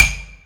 somen_catch.wav